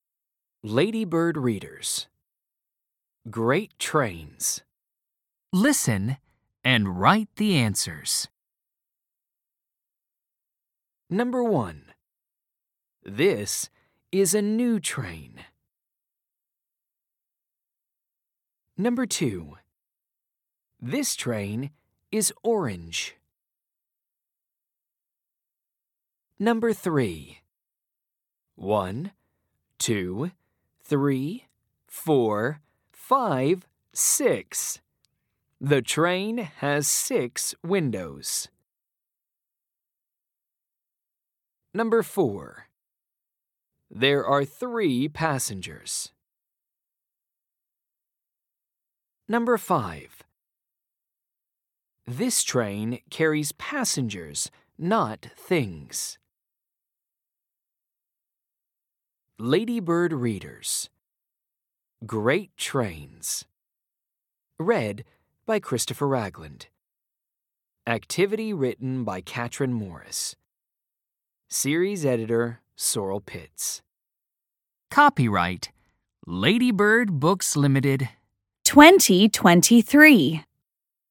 Audio US